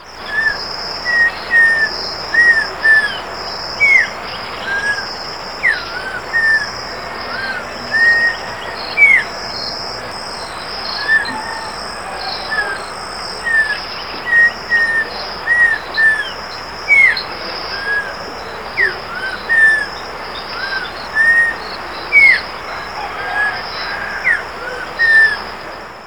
Audubon's Oriole